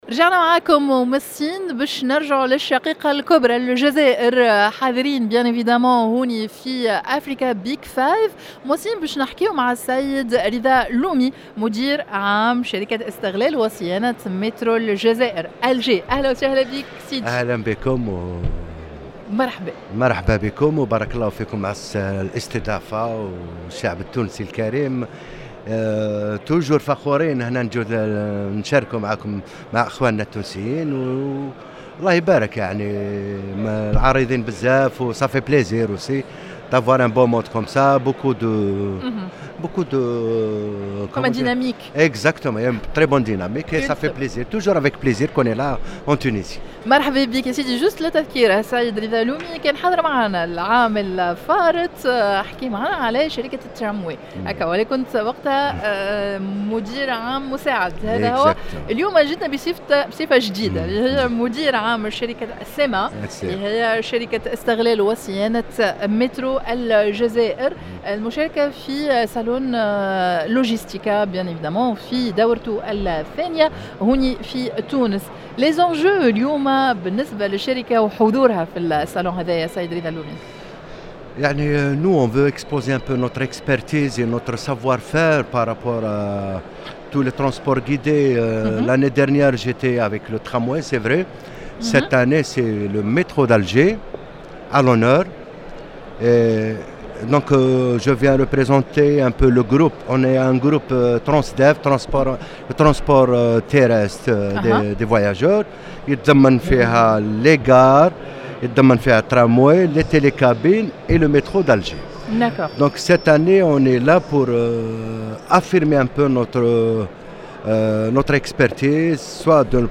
dans un plateau spécial en direct du palais des expositions El Kram à l’occasion de la 10ᵉ édition de PetroAfrica